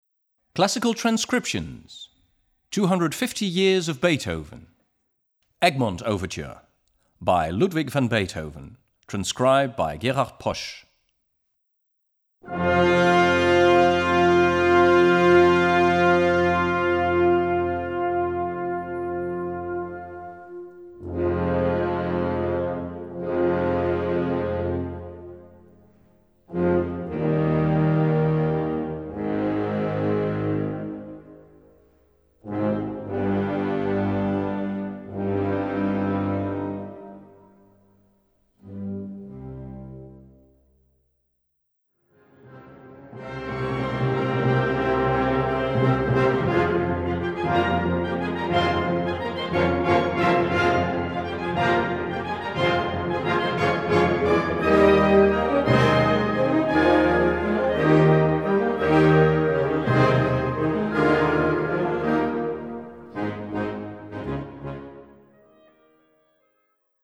Gattung: Ouvertüre
Besetzung: Blasorchester